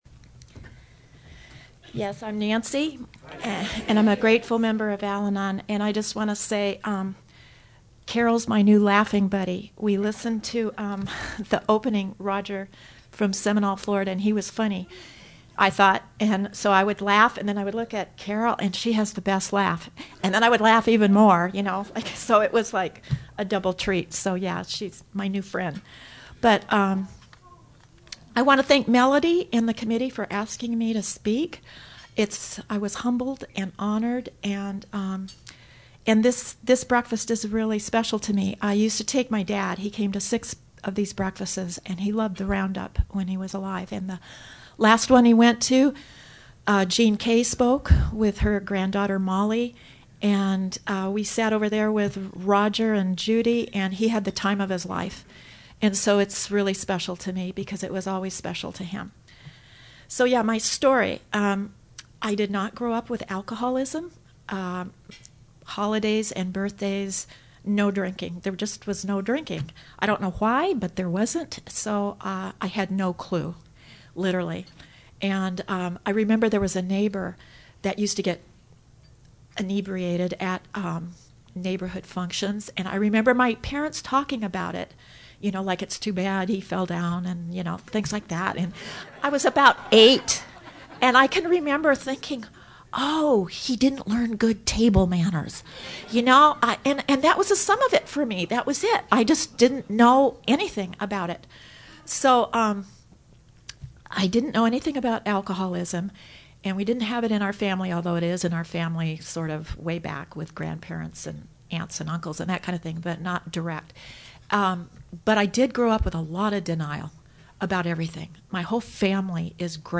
REDONDO BEACH CA- AFG FAMILY MEETING &#8211